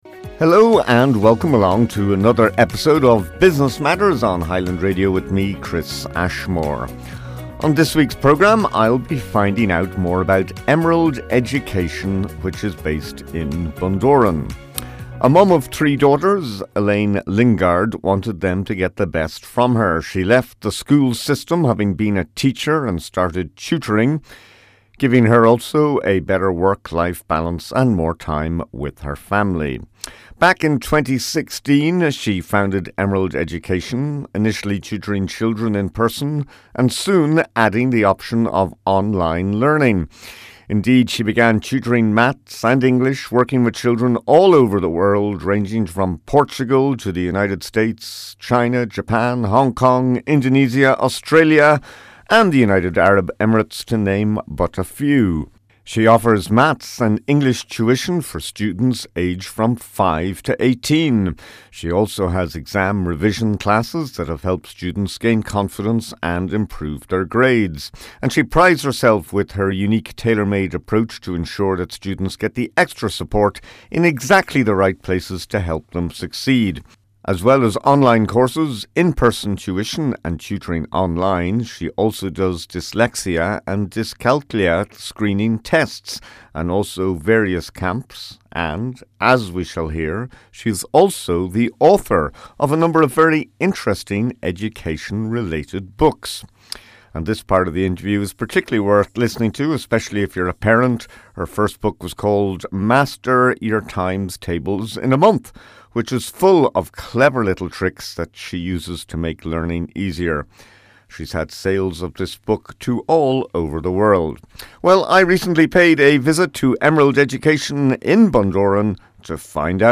This part of the interview is particularly worth listening to, especially if you are a parent.